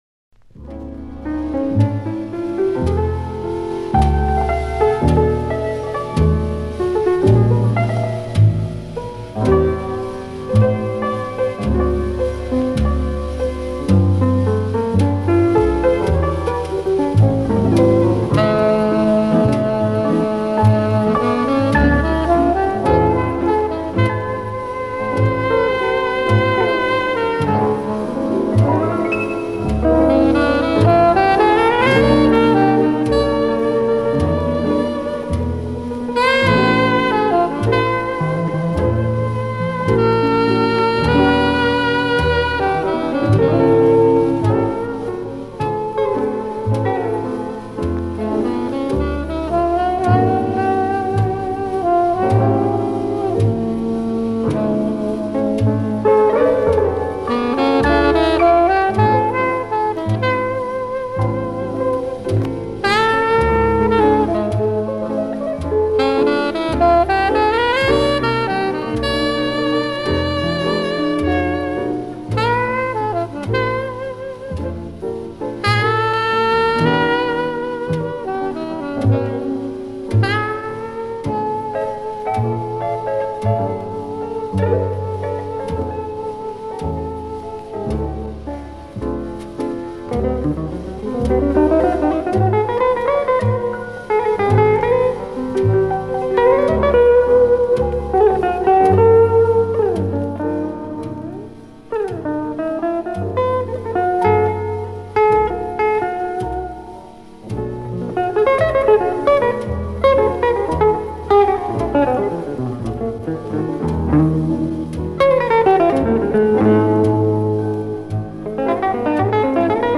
The classical style of Gypsy Jazz
electric guitar